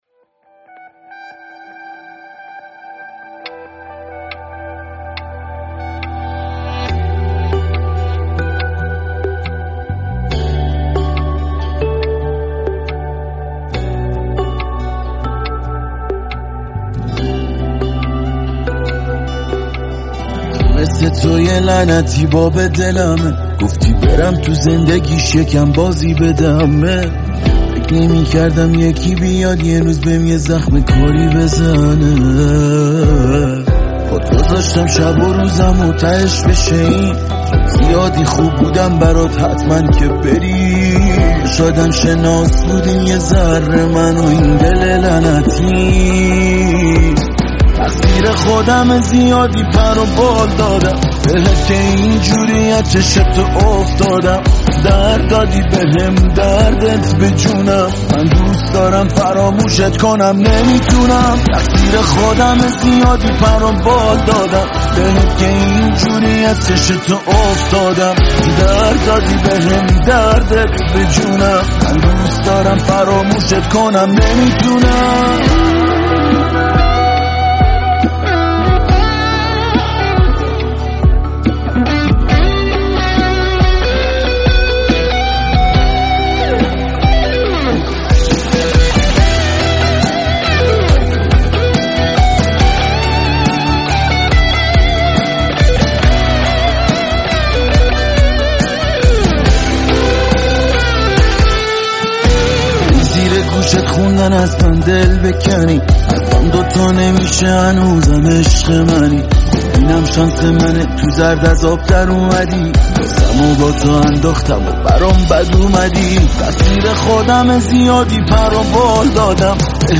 خواننده آهنگ